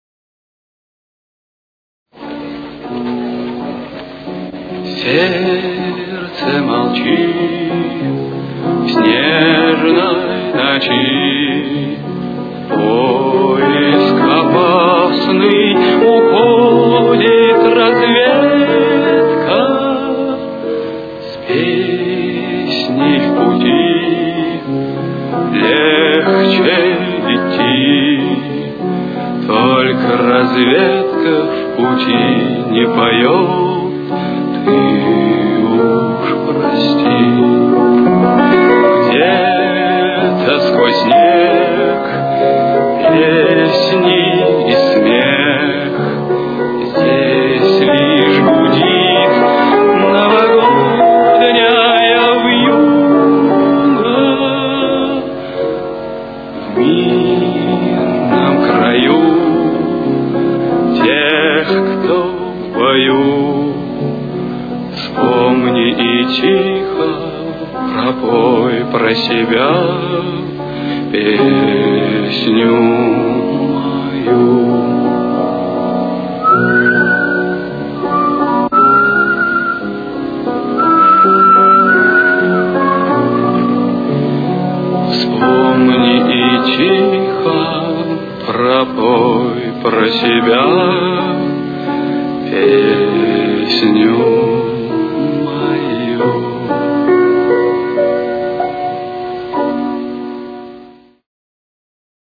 с очень низким качеством (16 – 32 кБит/с)
Си минор. Темп: 185.